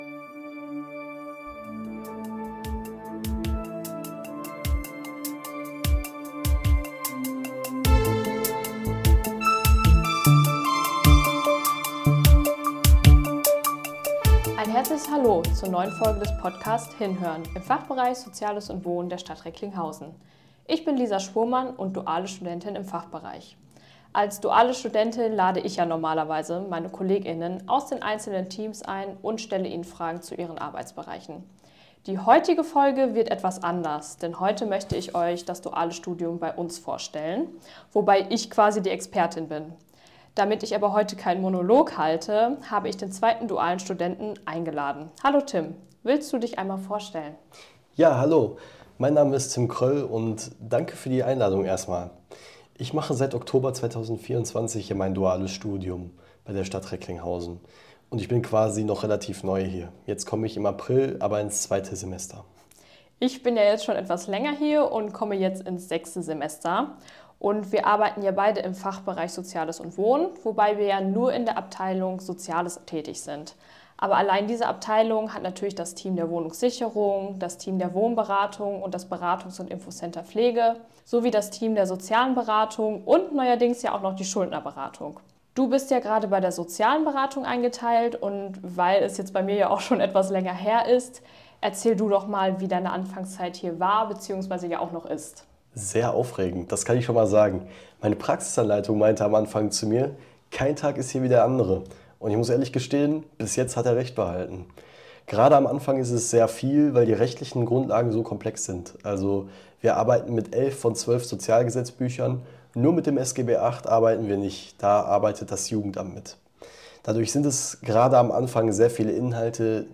In dieser Folge sprechen die zwei dual